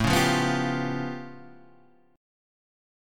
A Augmented 9th
A+9 chord {5 4 3 4 6 3} chord